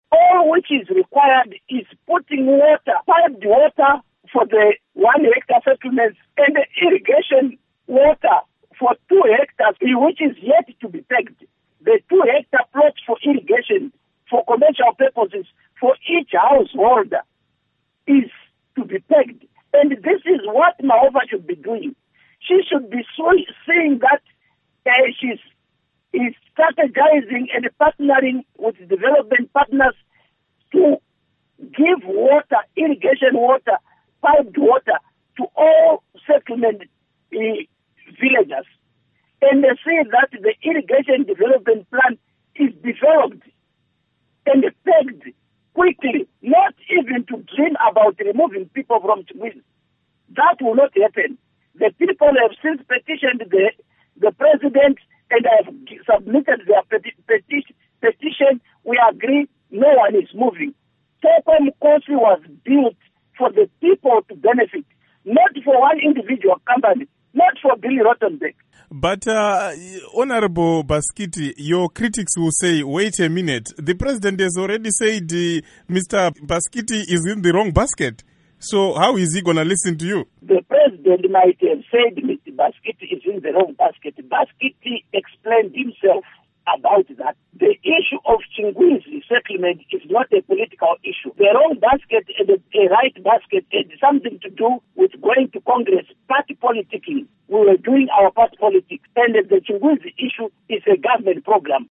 Interview With Kudakwashe Bhasikiti on Mahofa